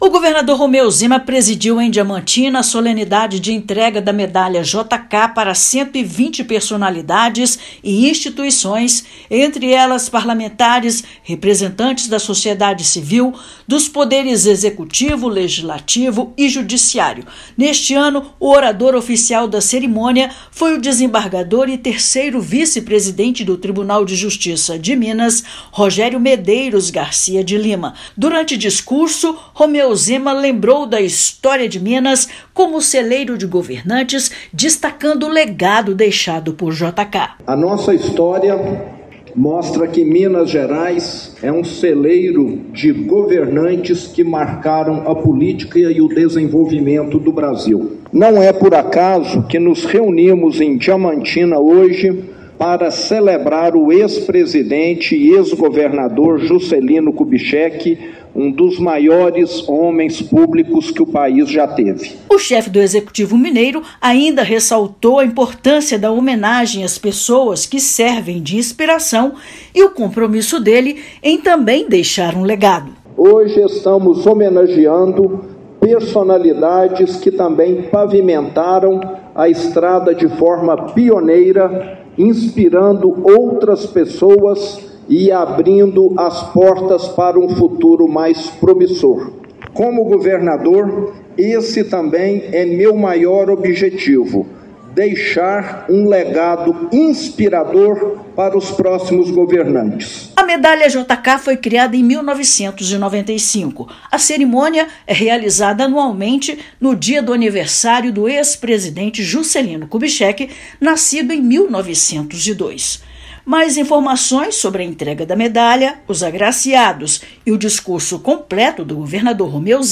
[RÁDIO] Governo de Minas entrega Medalha JK, em Diamantina, para 120 agraciados
Governador presidiu a cerimônia nesta quinta-feira (12/9), que teve participação de medalhistas de ouro em Paris e homenagem à mestre artesã Dona Izabel, referência do Vale do Jequitinhonha. Ouça matéria de rádio.
ENTREGA_MEDALHA_JK.mp3